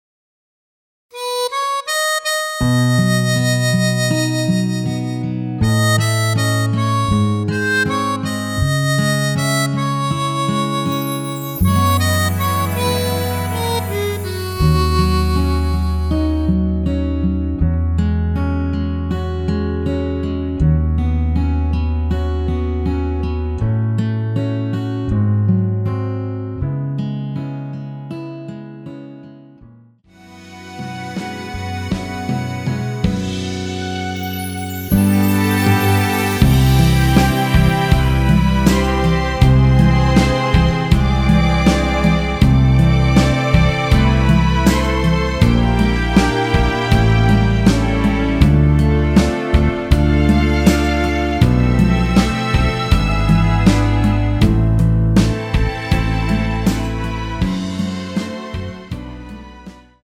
원키에서(-2)내린 MR입니다.
◈ 곡명 옆 (-1)은 반음 내림, (+1)은 반음 올림 입니다.
앞부분30초, 뒷부분30초씩 편집해서 올려 드리고 있습니다.
중간에 음이 끈어지고 다시 나오는 이유는